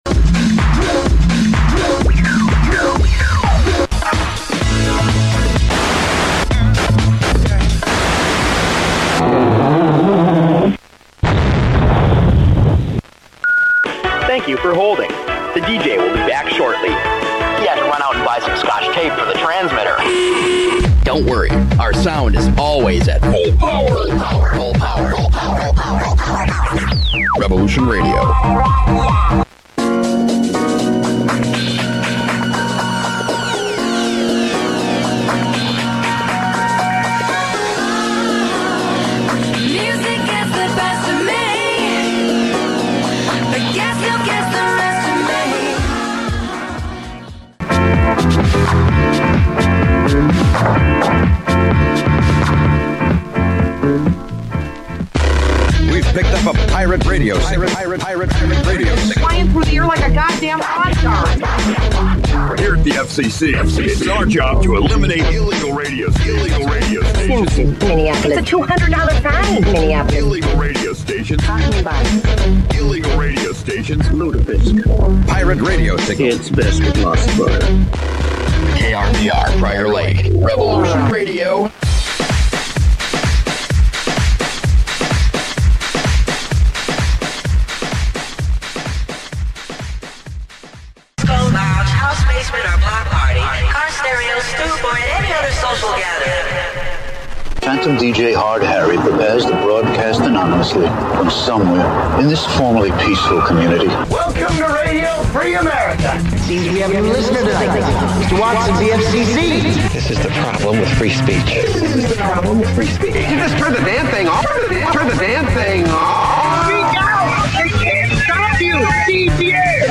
UNLICENSED RADIO STATIONS
Stations below were heard in the Minneapolis/St. Paul, Minnesota area.